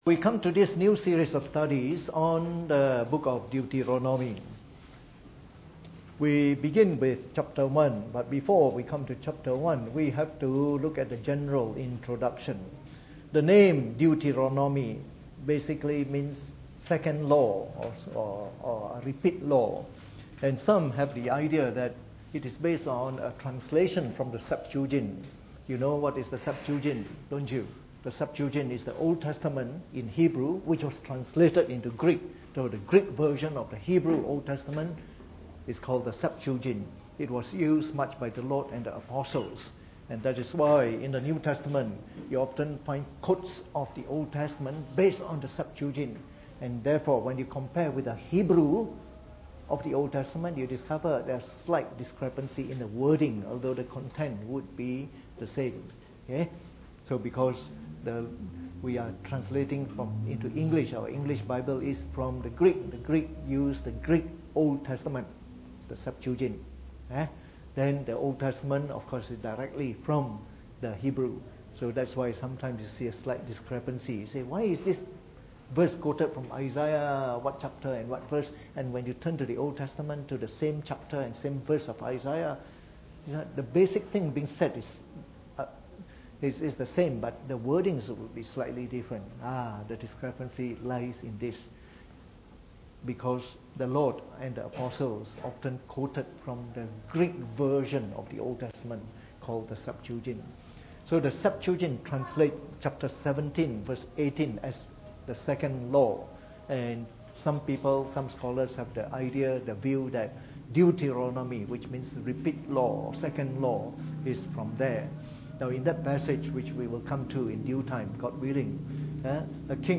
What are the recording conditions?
Preached on the 3rd of January 2018 during the Bible Study, from our series on the book of Deuteronomy.